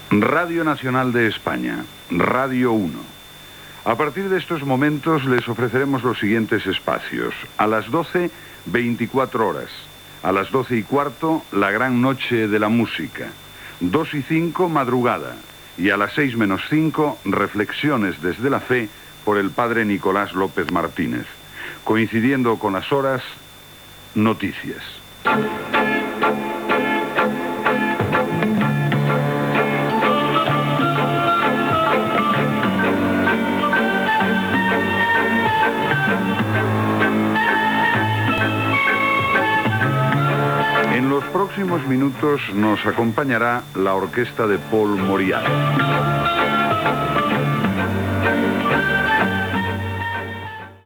Identificació de l'emissora com Radio 1, avenç de programació i tema musical